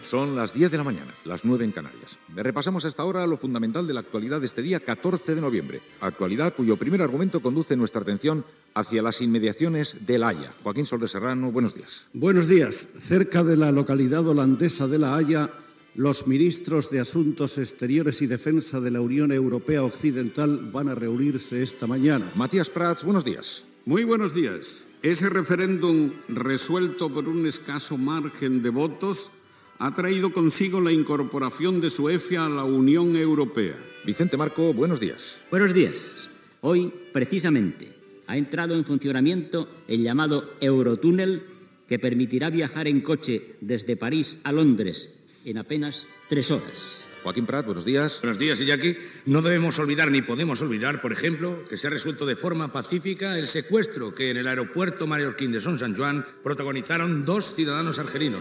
Especial 70 anys de Radio Barcelona: butlletí de notícies locutat per Joaquín Soler Serrano, Matías Prats, Vicente Marco i Joaquín Prat.
Info-entreteniment